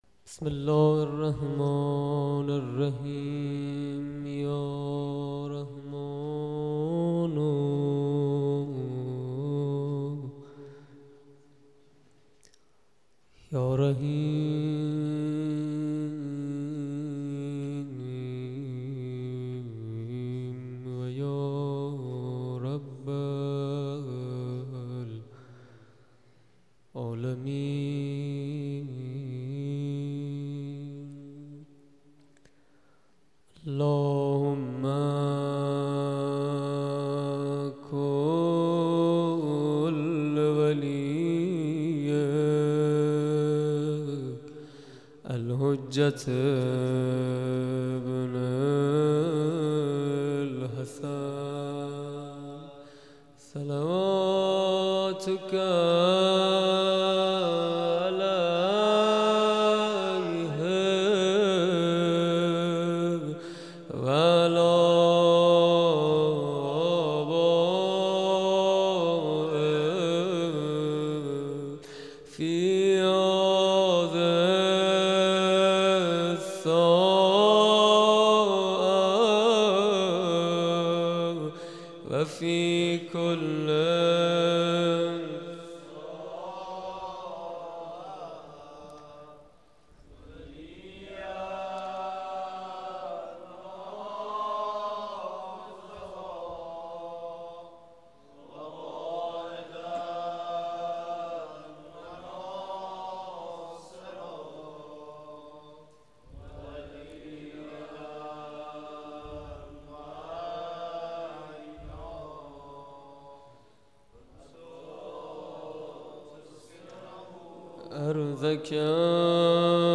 مناجات با آقا امام زمان